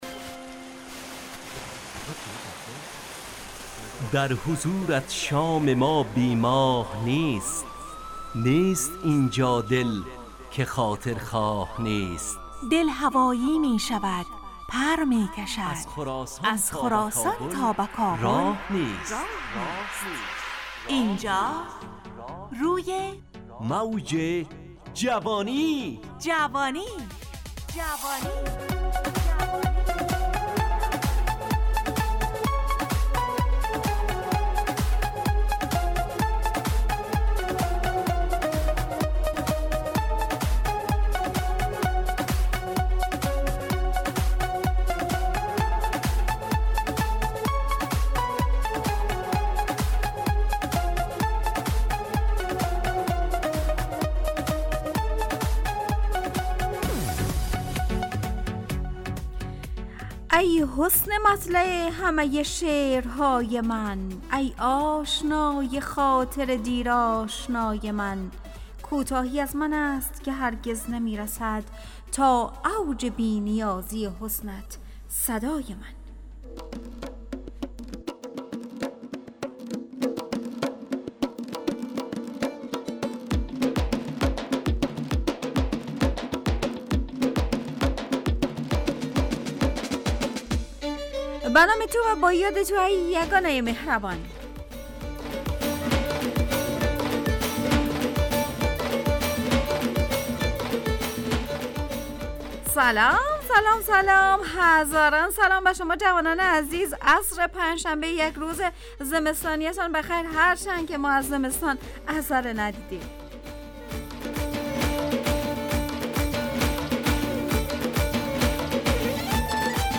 همراه با ترانه و موسیقی مدت برنامه 70 دقیقه . بحث محوری این هفته (دغدغه)
روی موج جوانی برنامه ای عصرانه و شاد